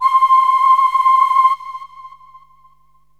SYNTH GENERAL-1 0007.wav